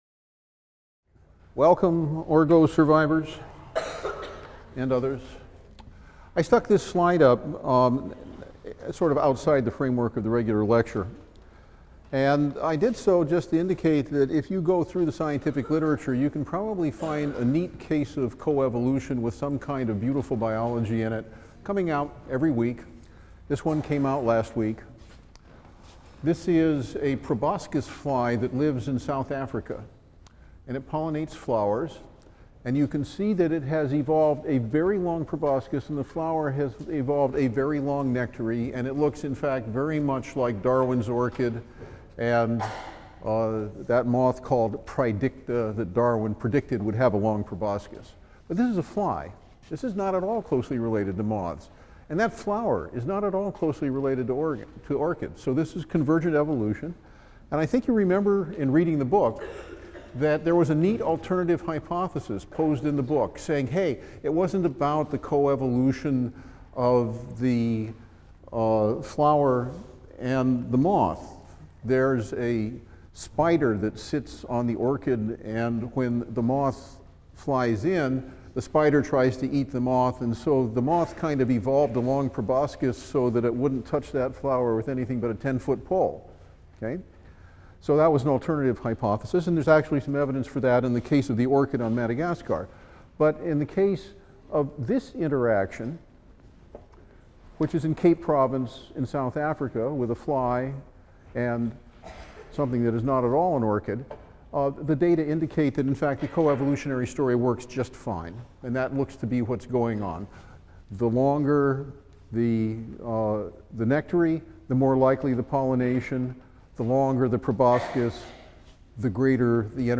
E&EB 122 - Lecture 20 - Coevolution | Open Yale Courses